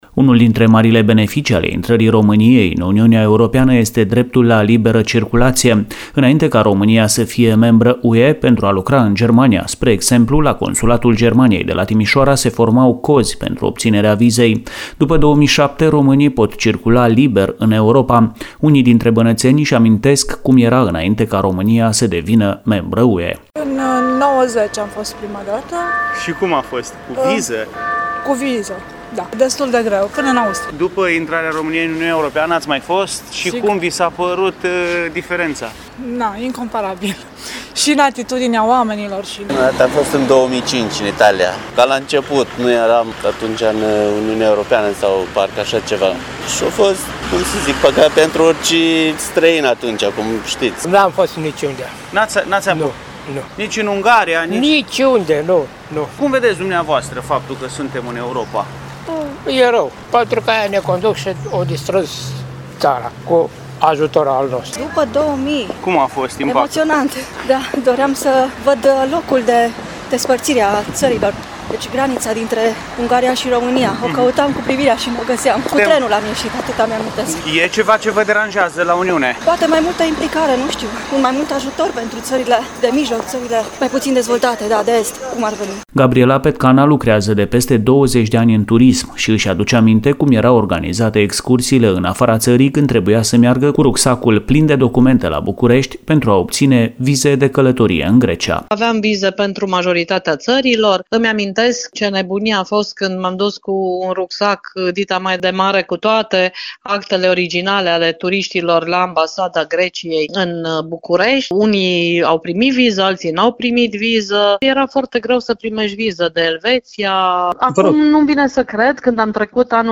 Unii dintre bănățeni își amintesc cum era înainte ca românia să facă parte din Uniunea Europeană.